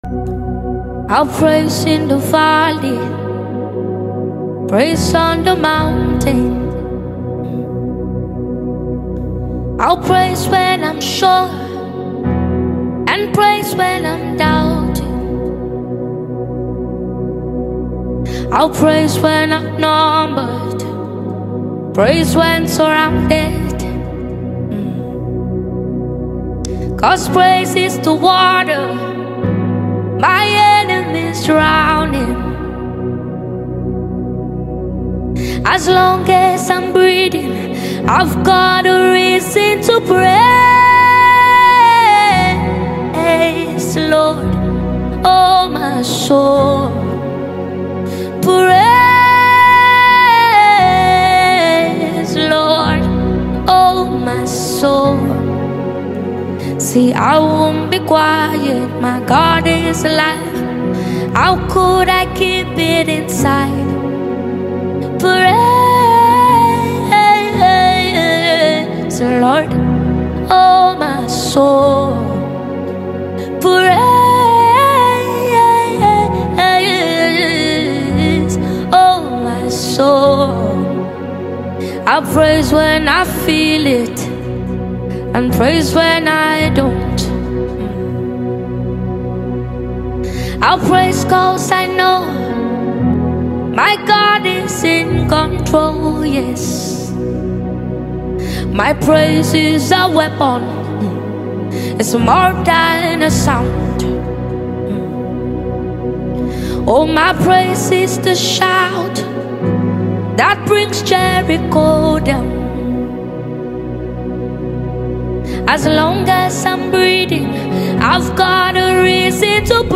Prominent Nigerian Singer